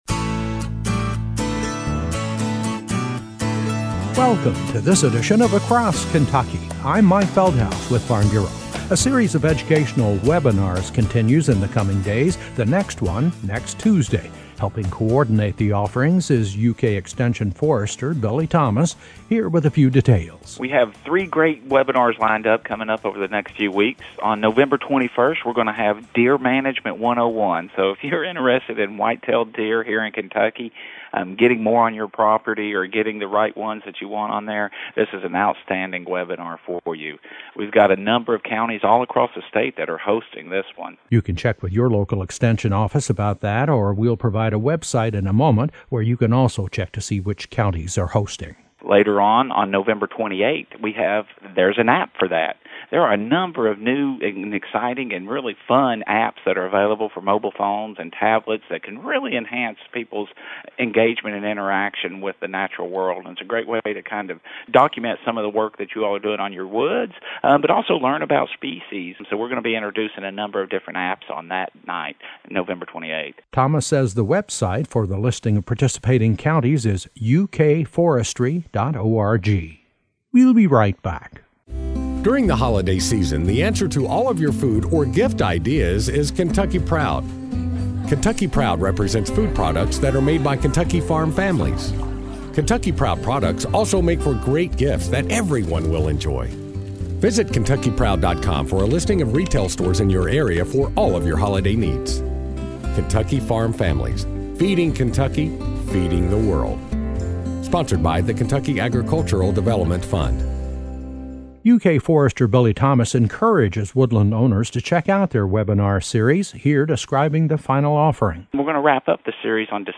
A report on a series of educational webinars that’s being hosted by UK Forestry for landowners across the state.